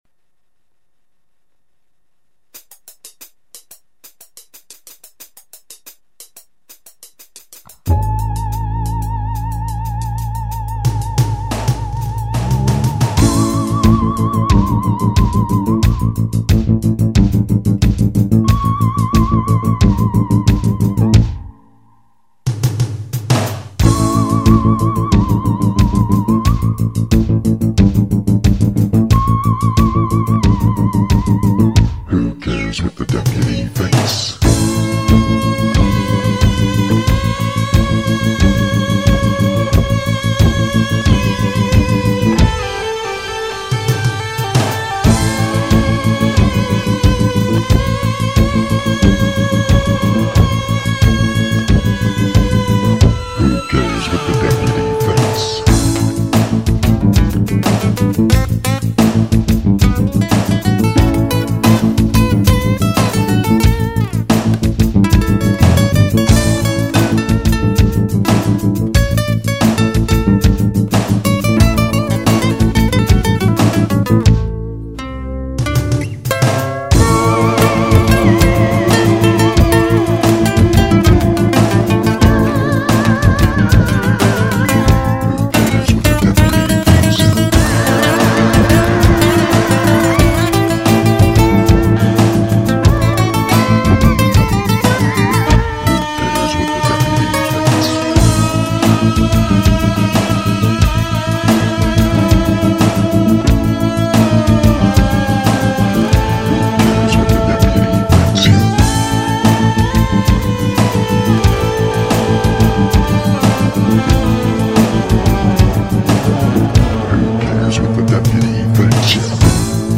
He programmed the drum track on the Roland R-8 machine.
That spacey, wild electric lead guitar?